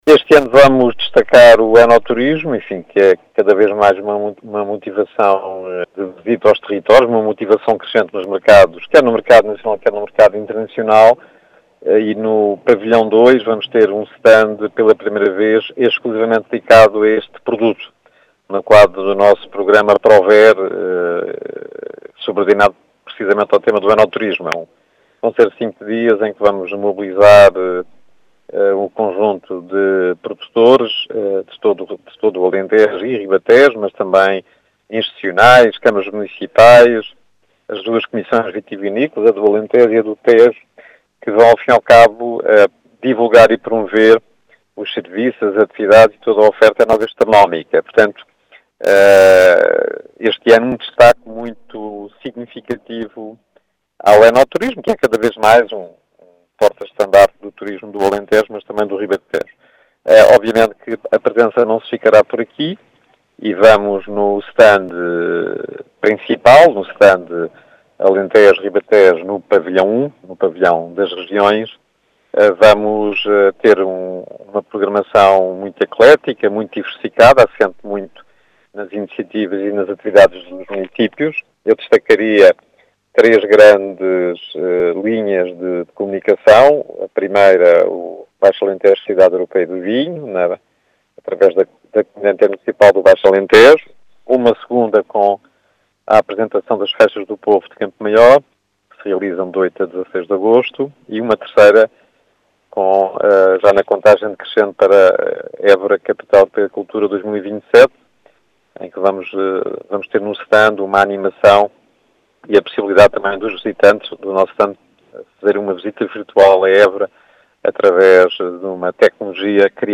As explicações são do presidente da Entidade Regional de Turismo do Alentejo e Ribatejo, José Santos, que em entrevista à Rádio Vidigueira fala desta participação na BTL, que tem o enoturismo como bandeira.